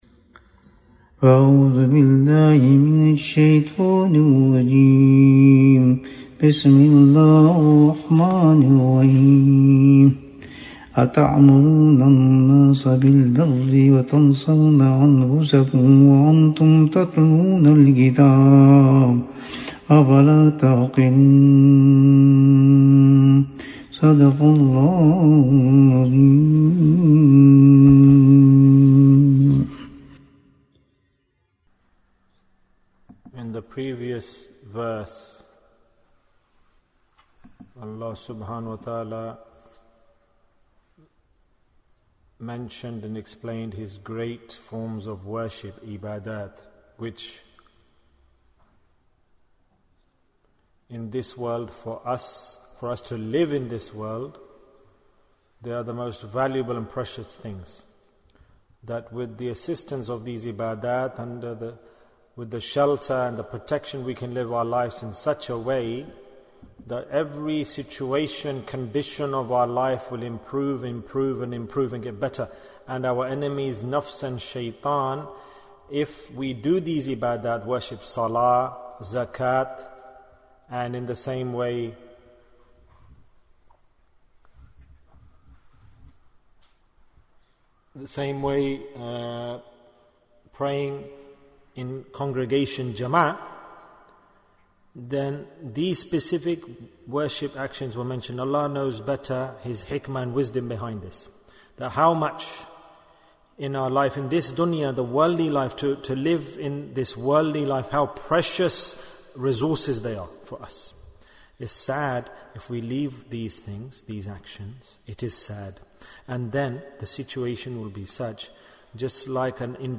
- Dars 50 Bayan, 61 minutes 2nd September, 2020 Click for Urdu Download Audio Comments DARS 50 What was the first Dar-ul-Uloom?